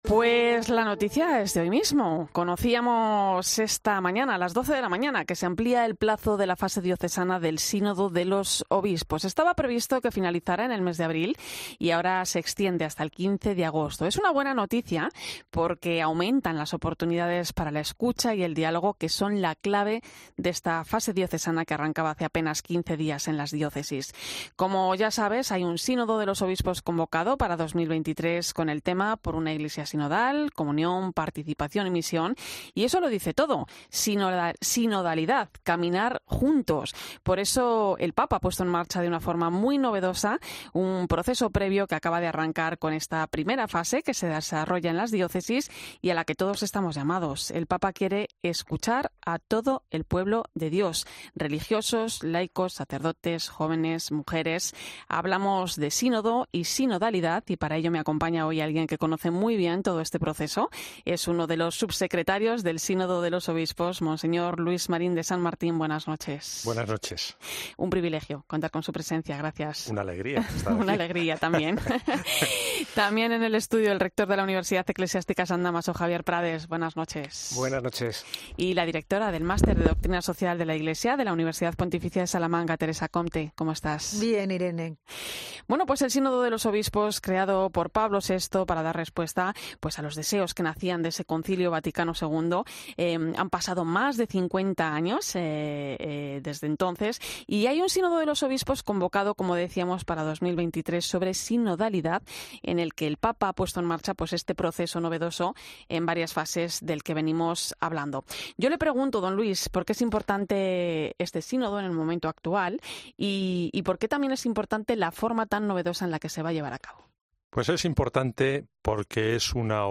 El subsecretario del Sínodo de los Obispos explica en 'La Linterna de la Iglesia' lo que es el Sínodo y participa en la mesa de análisis opinando...